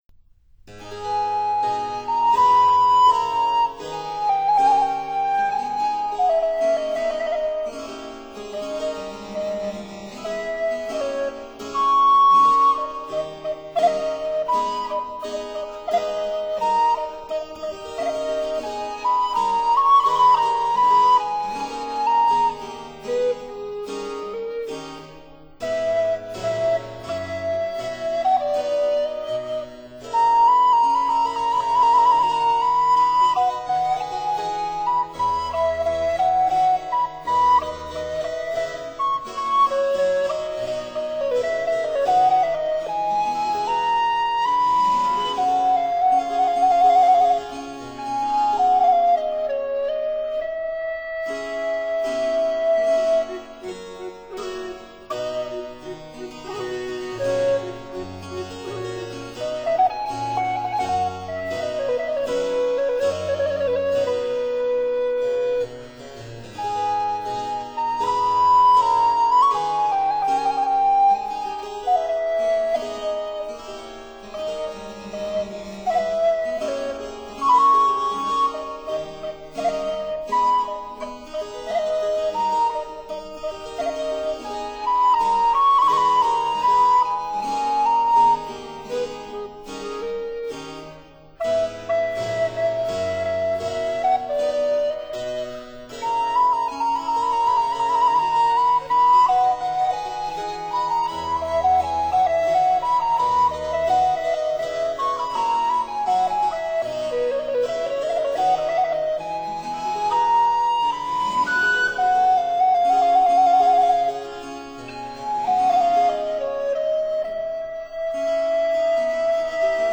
巴洛克樂曲
recorders
baroque cello
harpsichord
lute